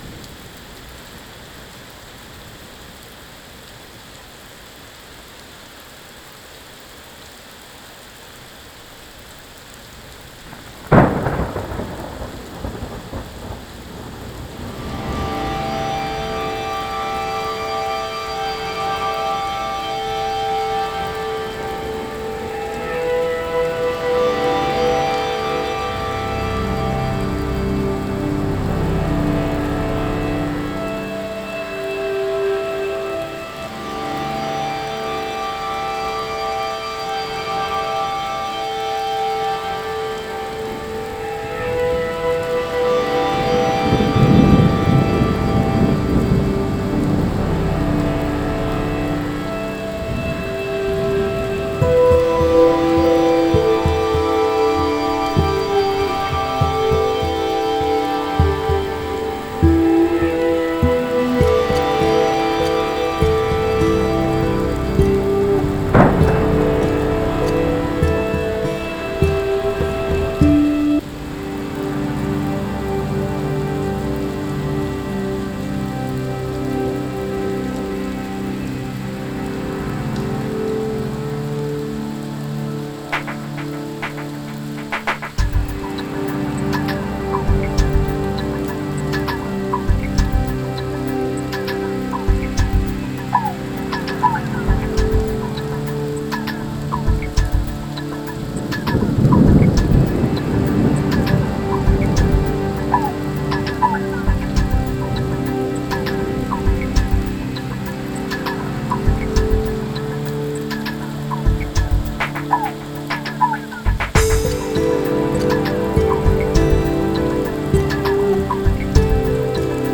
Музыка релакс